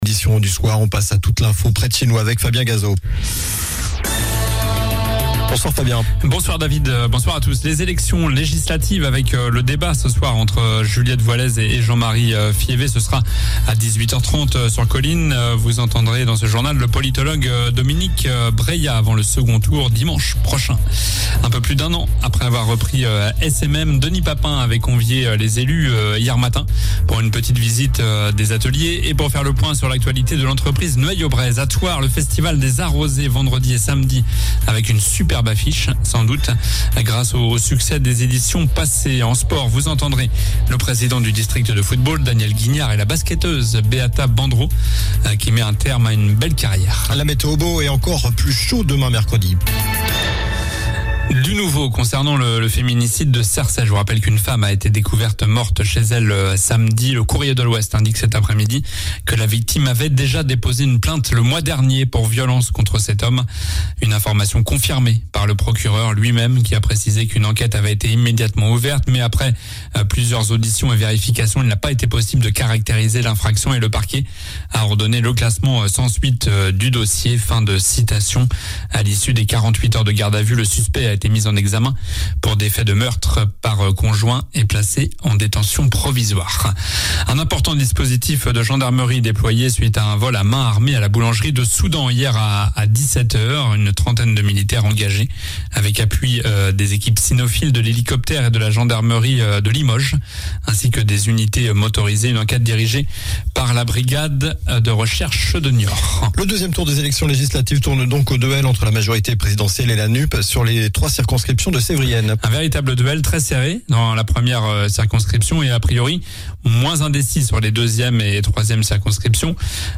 Journal du mardi 14 juin (soir)